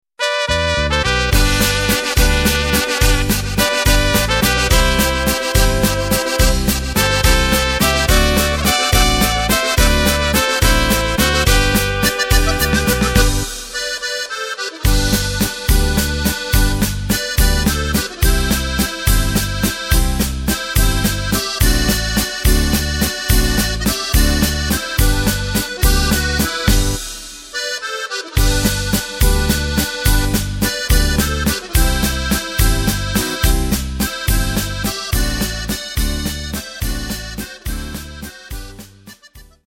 Takt:          3/4
Tempo:         213.00
Tonart:            G
Combo Version des trad. Walzers!
Playback mp3 Demo